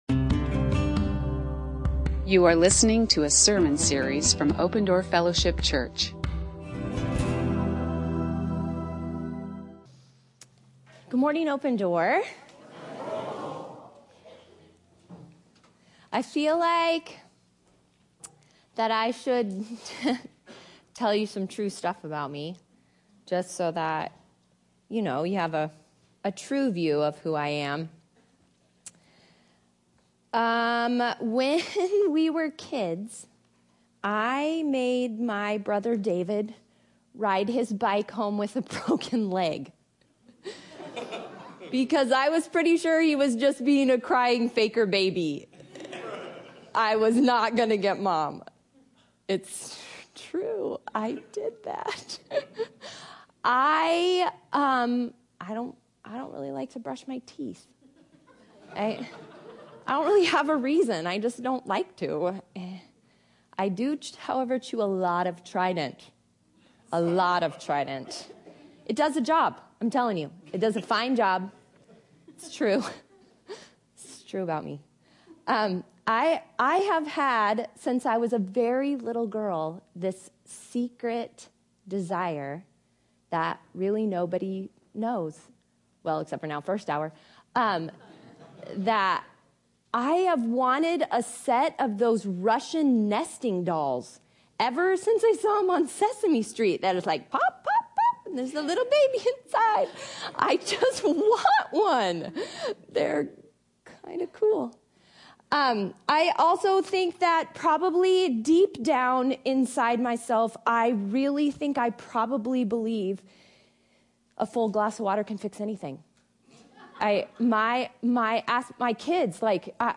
You are listening to an audio recording of Open Door Fellowship Church in Phoenix, Arizona.